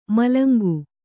♪ malangu